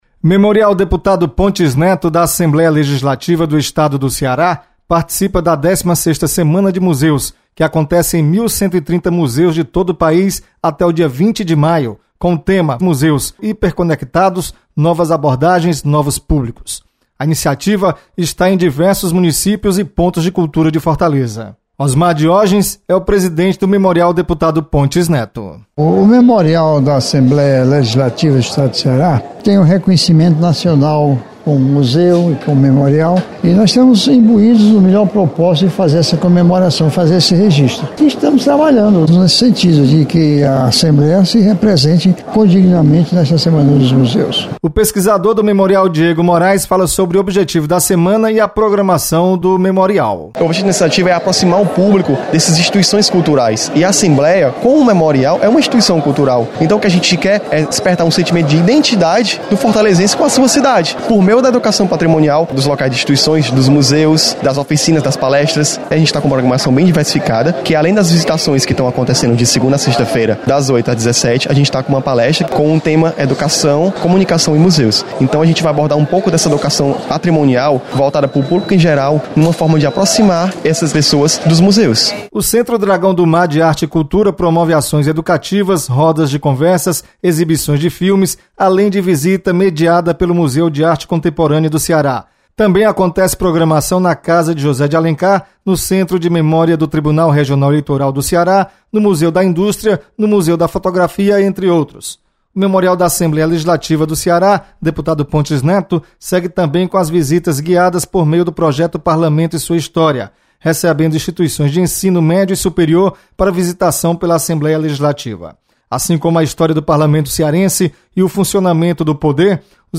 Memorial Pontes Neto participa da 16ª Semana de Museus. Repórter